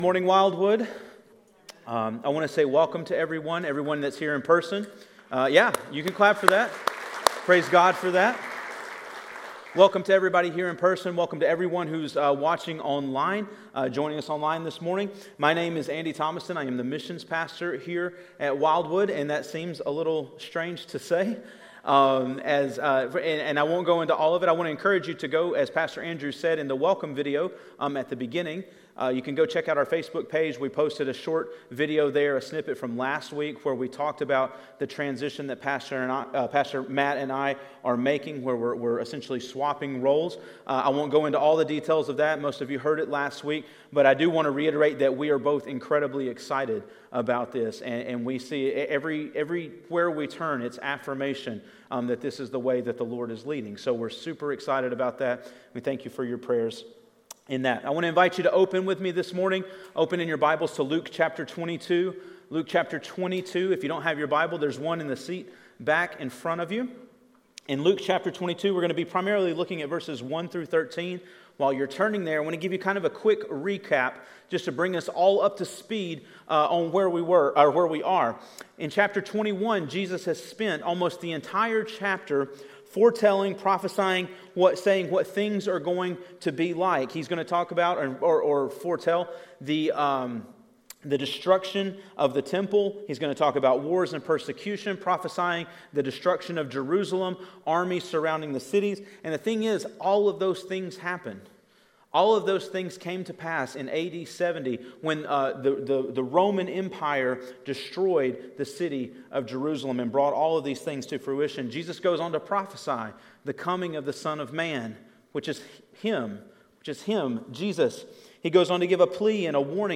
A message from the series "Advent 2020."